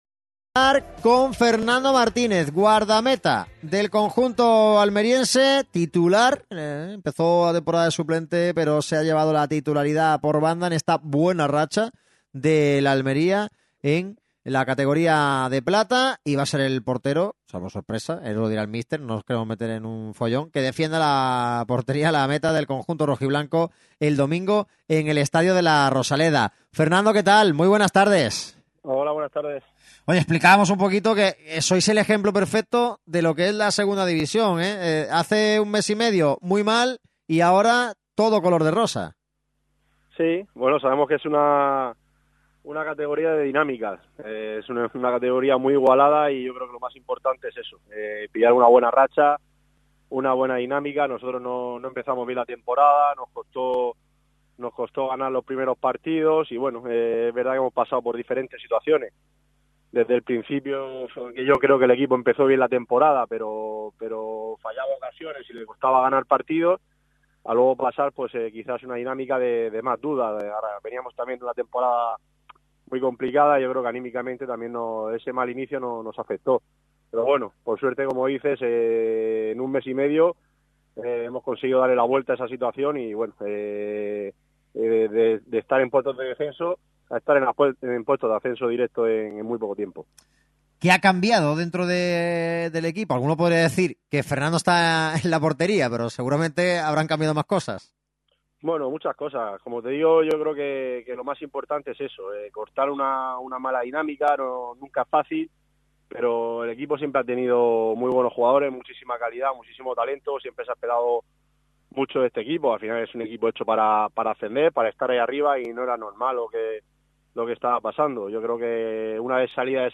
El portero y capitán de la UD Almería, Fernando Martínez, habla en exclusiva en Radio MARCA Málaga.